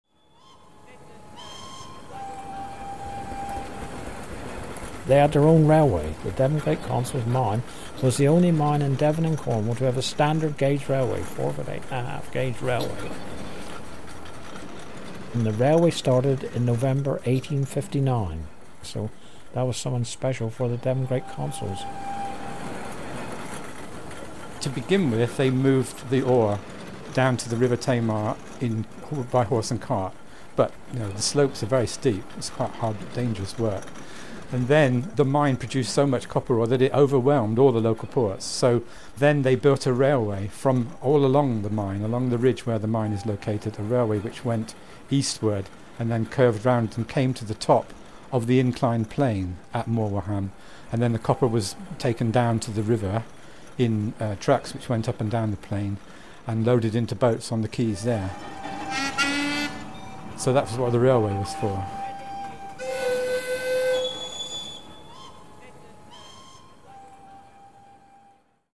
Listen to locals share memories from the past.